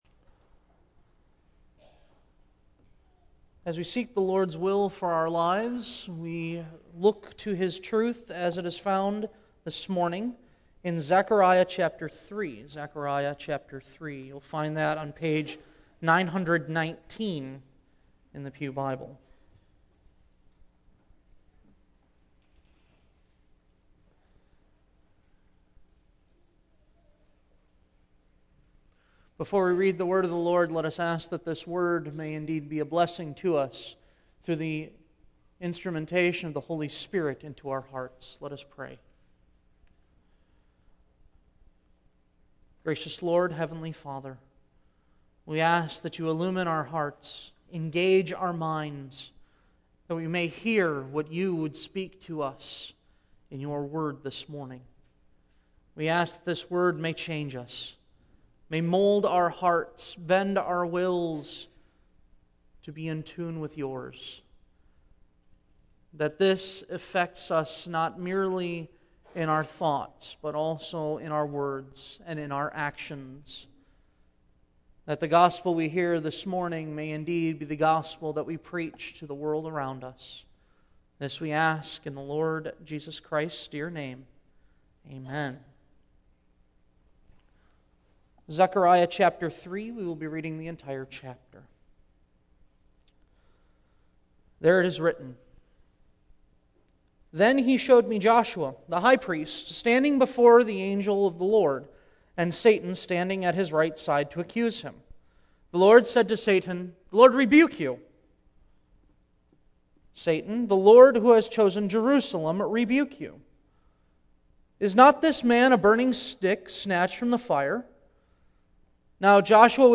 Series: Single Sermons Passage: Zechariah 3:1-10